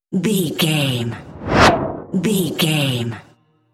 Whoosh fast bright
Sound Effects
Fast
bouncy
bright
driving
futuristic
intense
whoosh